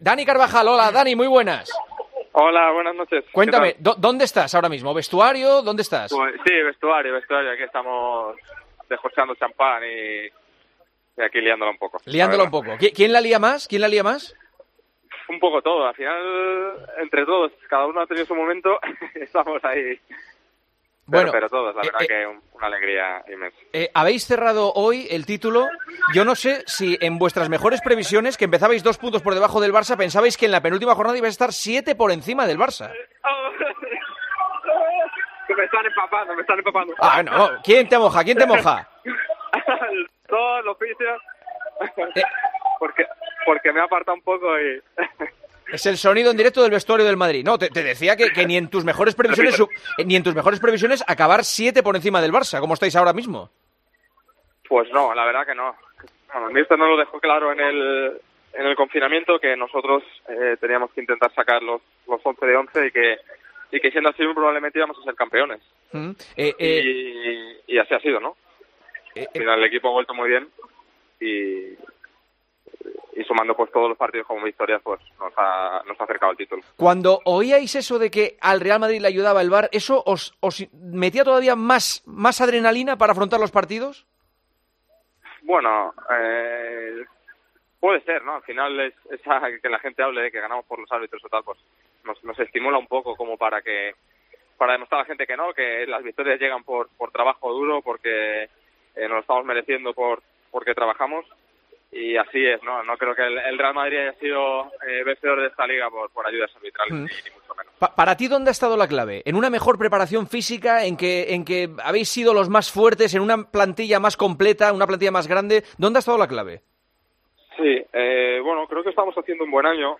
El futbolista del Real Madrid, Dani Carvajal, ha atendido a los micrófonos de El Partidazo de COPE desde dentro del vestuario en plena celebración de Liga.
“Me están bañando en champán”, ha explicado entre risas.